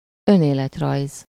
Ääntäminen
France (Paris): IPA: /ky.ʁi.ky.lɔm vi.te/